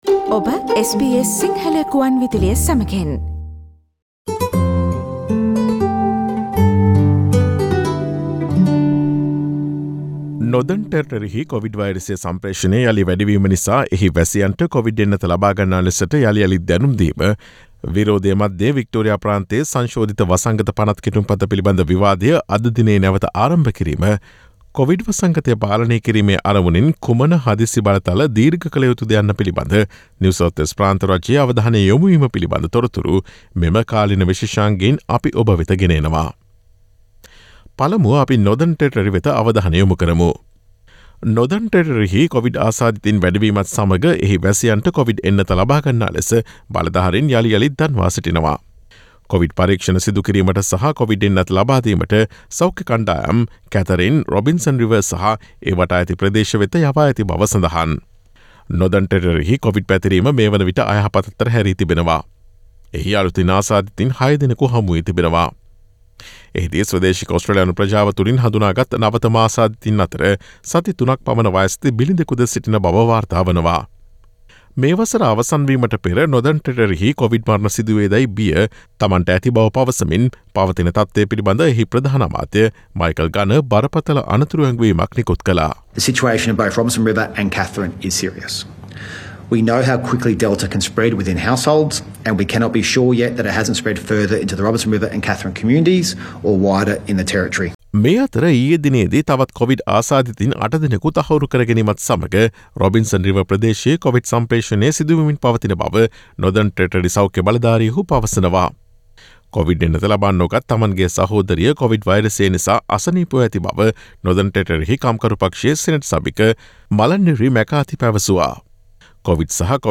Northern Territory , වික්ටෝරියා, නිව් සවුත් වේල්ස්, ක්වීන්ස්ලන්තය ඇතුළු ඔස්ට්‍රේලියාවෙන් වාර්තා වන නවතම කොවිඩ් තතු විත්ති රැගත් නොවැම්බර් 18 වන දා බ්‍රහස්පතින්දා ප්‍රචාරය වූ SBS සිංහල සේවයේ කාලීන තොරතුරු විශේෂාංගයට සවන්දෙන්න.